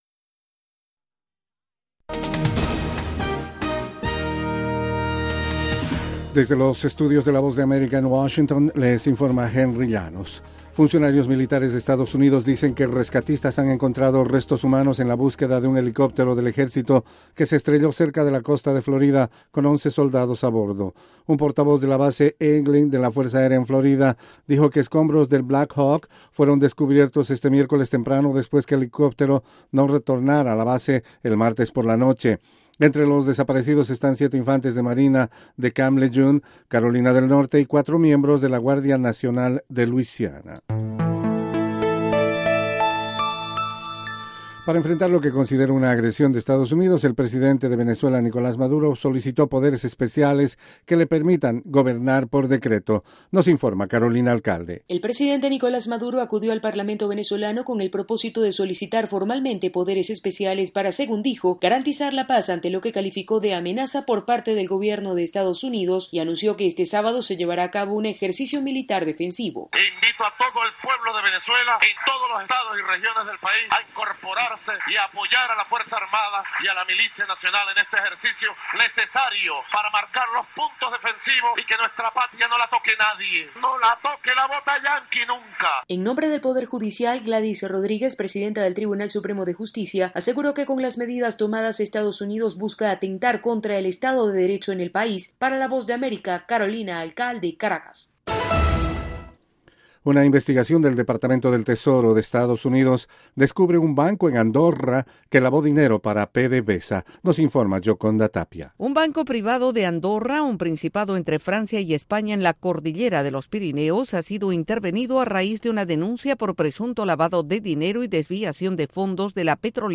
Informativo VOASAT